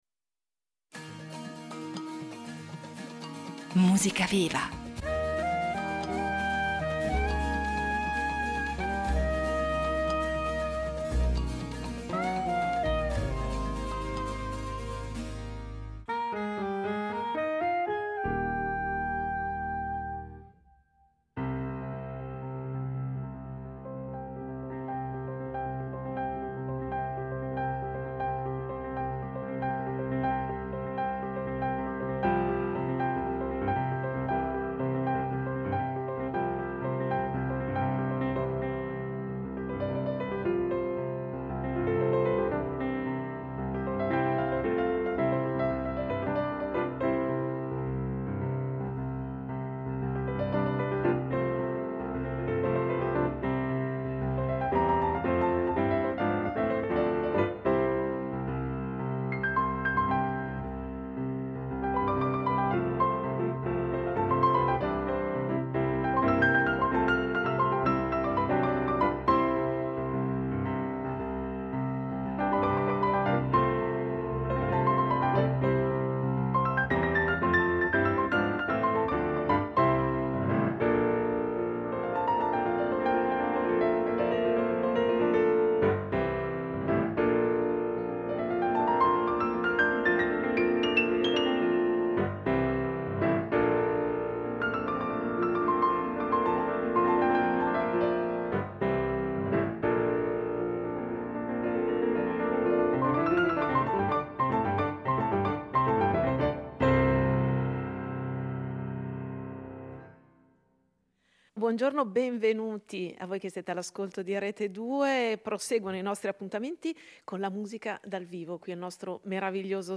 pianoforte
per pianoforte a quattro mani